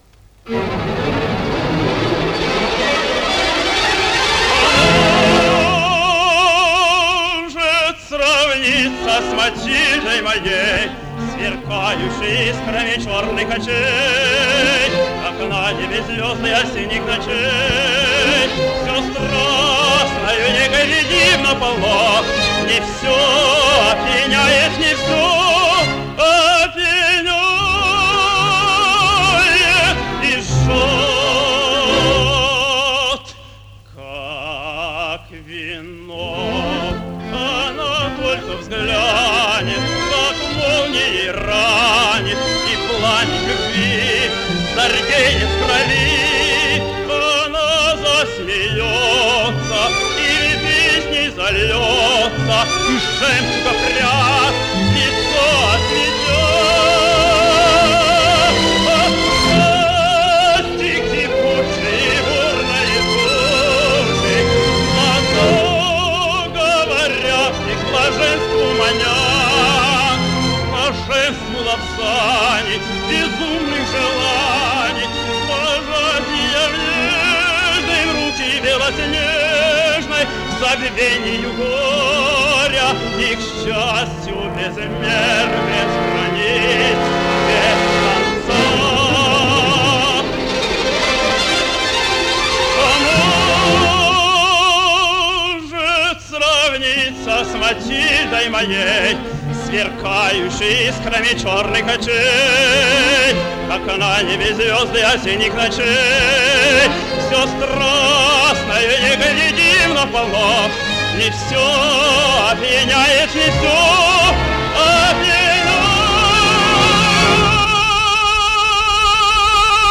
14 - П.И.Чайковский. Иоланта - Ария Роберта (Пантелеймон Норцов) (1940)